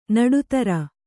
♪ naḍu tara